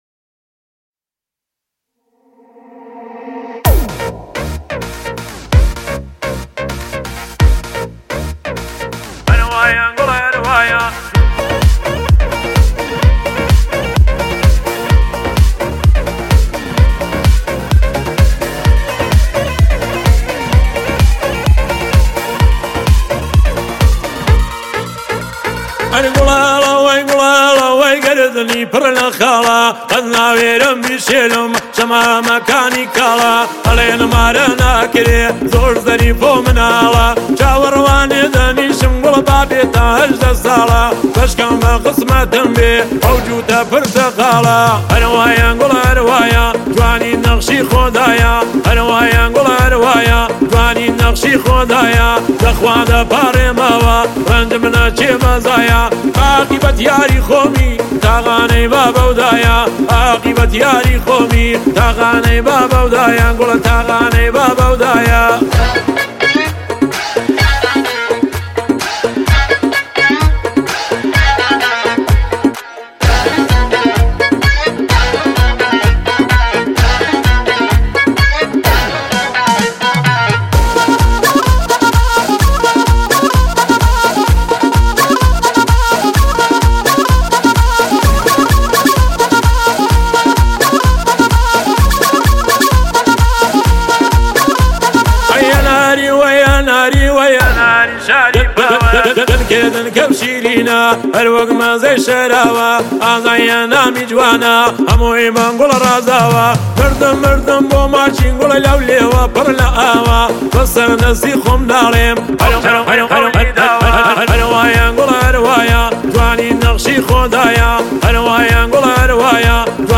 آهنگ کردی شاد آهنگ های پرطرفدار کردی